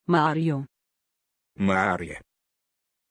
Aussprache von Maarja
pronunciation-maarja-ru.mp3